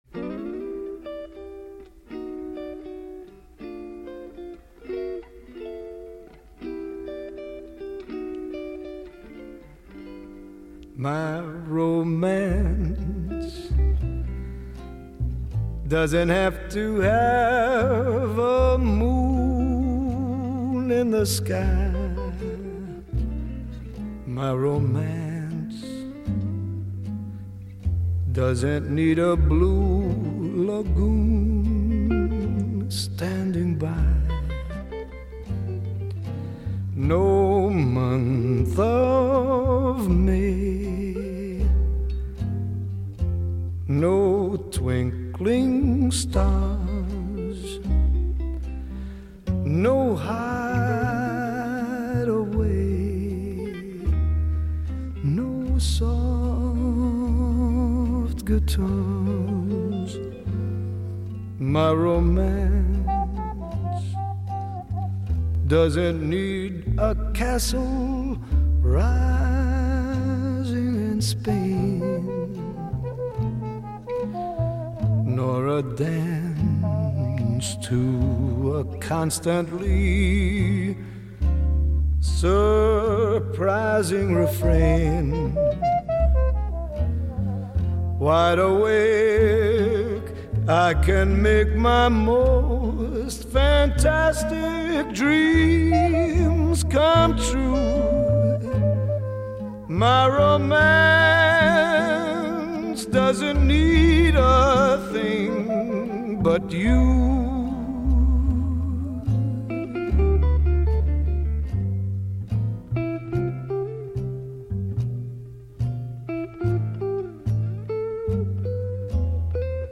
Light, dreamy, and romantic.
ClassicCroonerMusicRomance